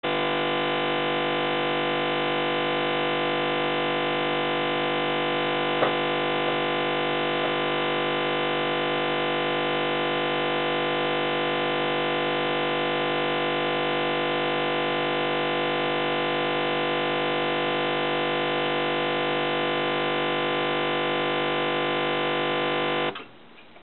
Звуки зависания, сбоя программы